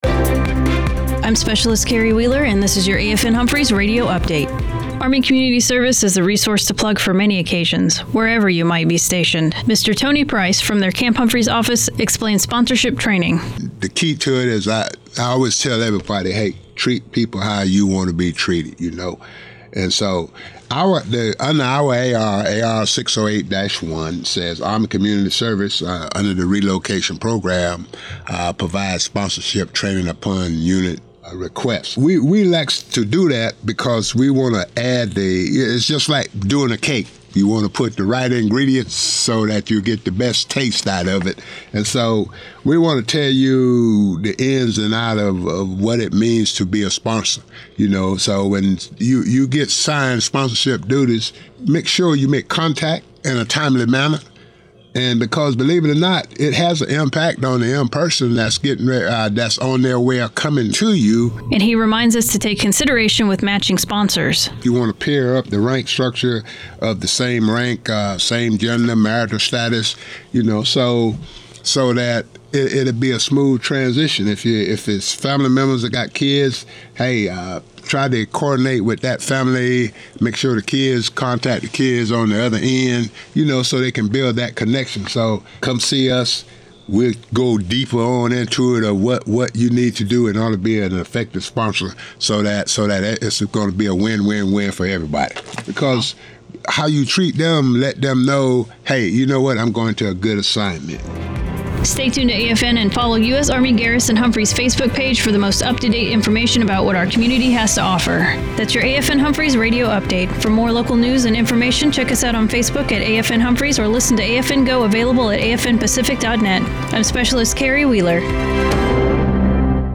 AFN Humphreys